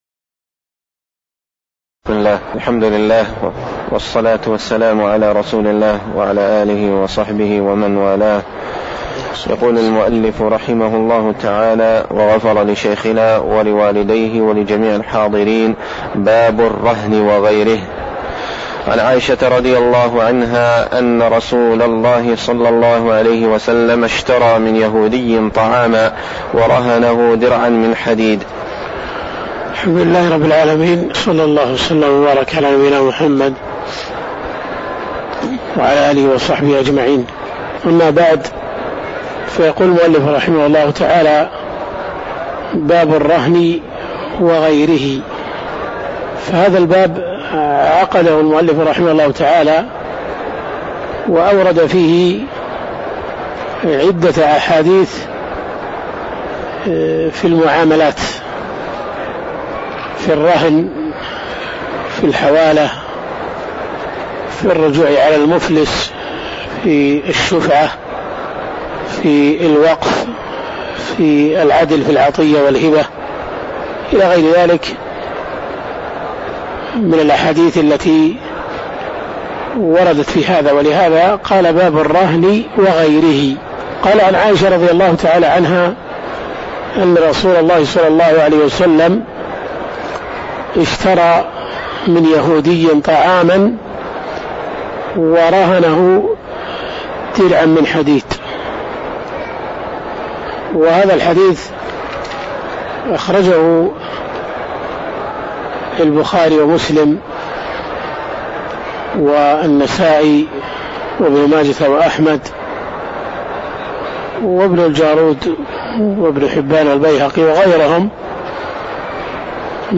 تاريخ النشر ٨ ربيع الأول ١٤٣٩ هـ المكان: المسجد النبوي الشيخ